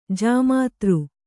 ♪ jāmātř